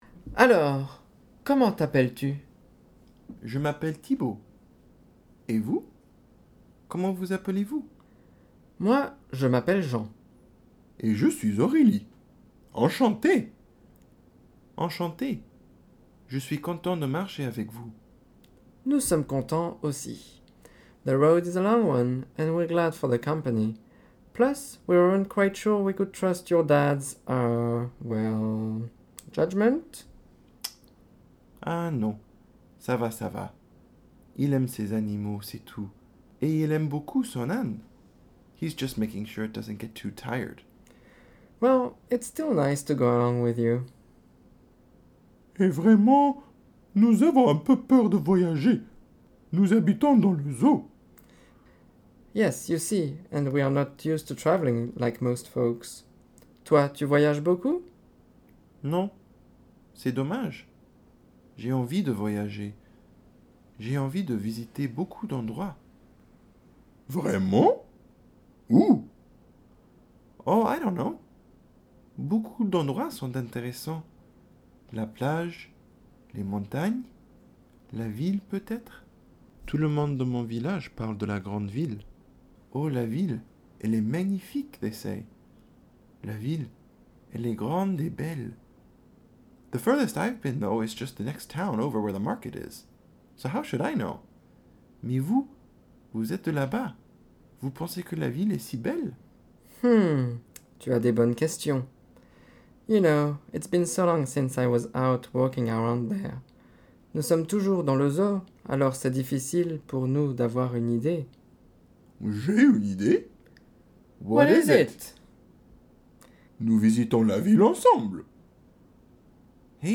The product feature the dialogues, grammar chart chants, complete vocabulary, Conversation Journal words and phrases, Say It Aloud exercises, dicteés, and more!